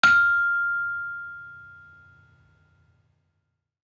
Saron-5-F5-f.wav